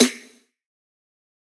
taiko-normal-hitclap.ogg